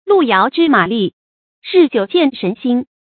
注音：ㄌㄨˋ ㄧㄠˊ ㄓㄧ ㄇㄚˇ ㄌㄧˋ ，ㄖㄧˋ ㄐㄧㄨˇ ㄐㄧㄢˋ ㄖㄣˊ ㄒㄧㄣ
路遙知馬力，日久見人心的讀法